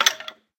skeletondeath.ogg